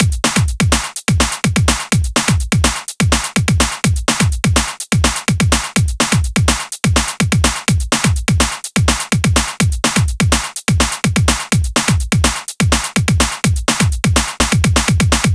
mainbeat125bpm.ogg